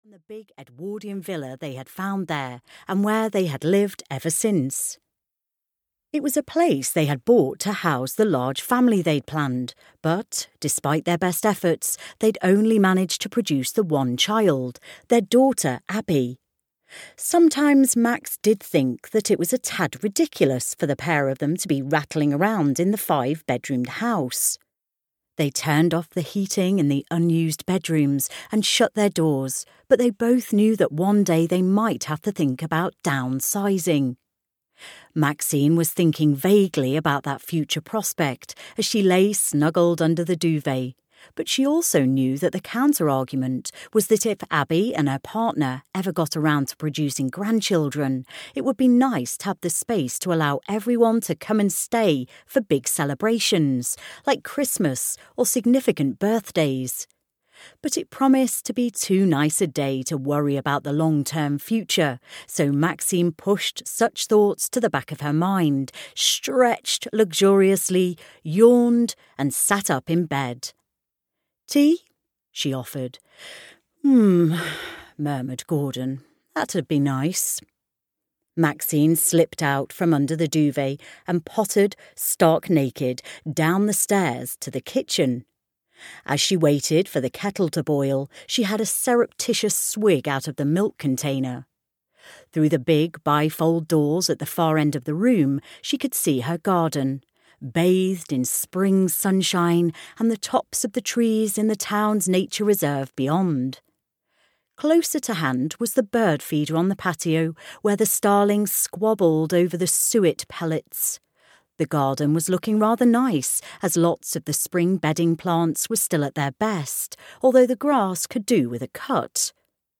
Homes and Hearths in Little Woodford (EN) audiokniha
Ukázka z knihy